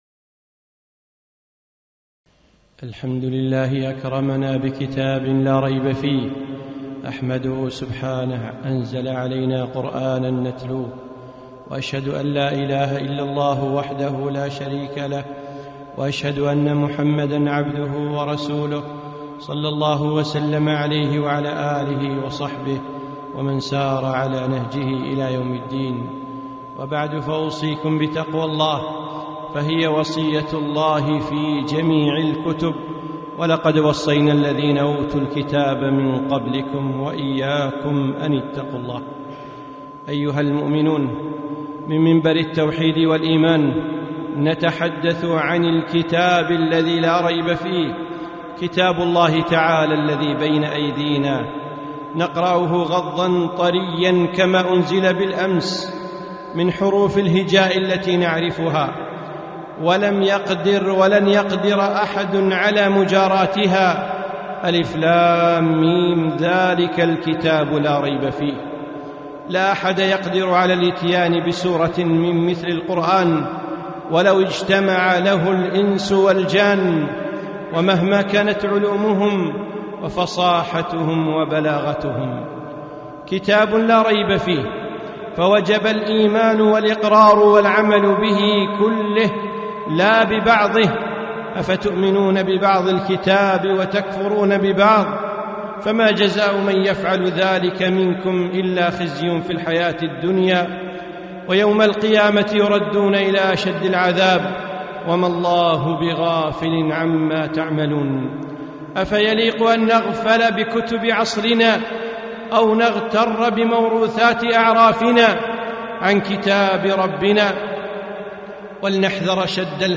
خطبة - كتاب لا ريب فيه